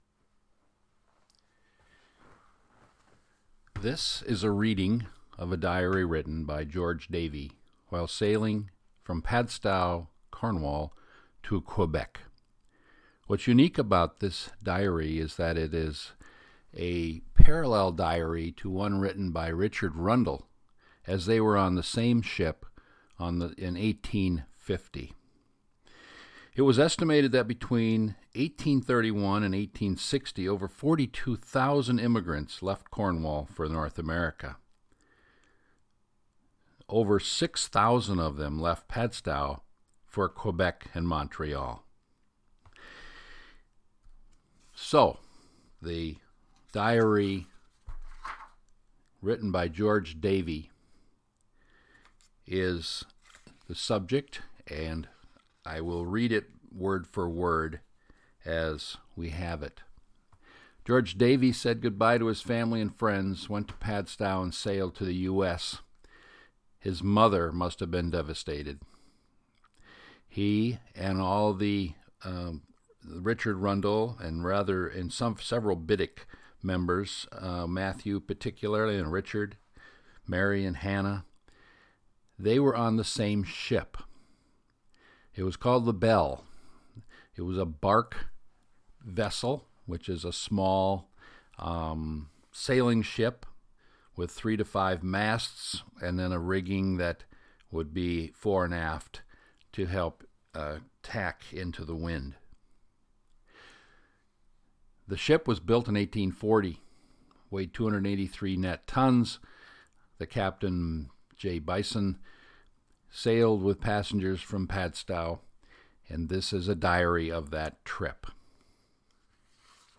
This is a reading of the Geo Davey Diary describing the Atlantic Crossing on the BELLE in 1850.